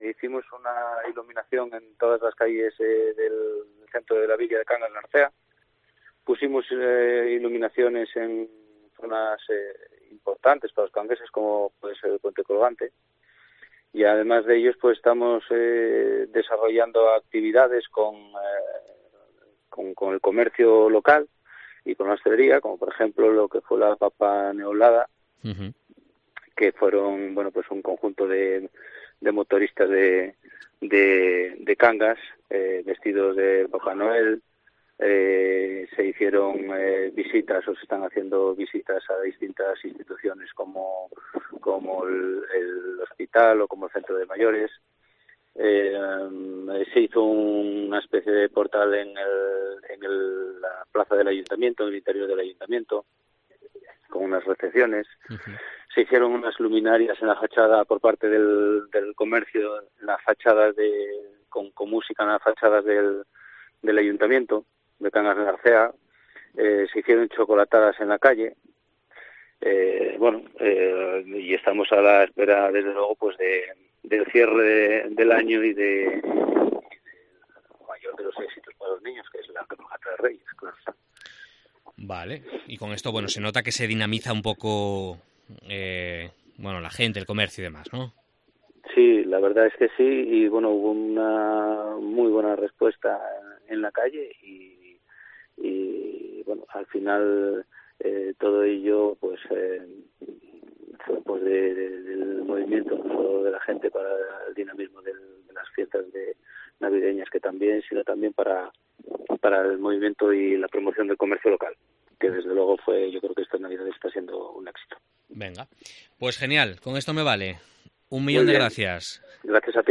El alcalde de Cangas del Narcea, José Luis Fontaniella, sobre la Navidad